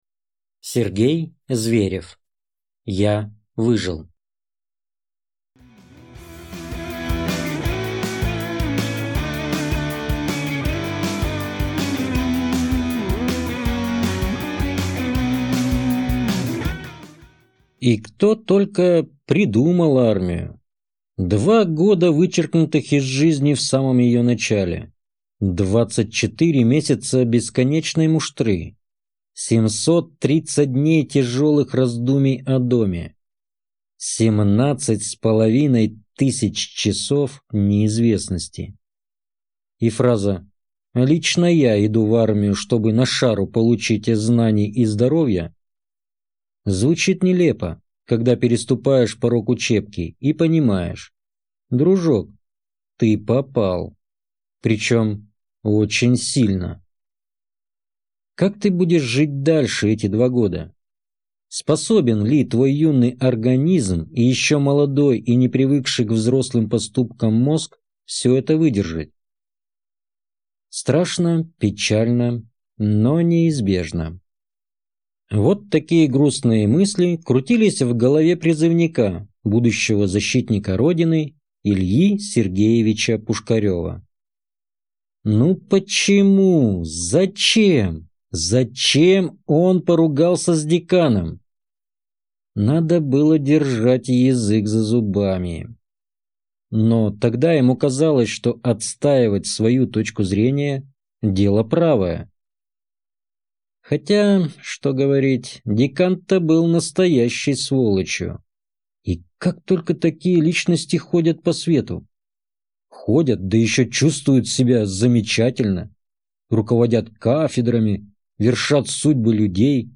Аудиокнига Я выжил | Библиотека аудиокниг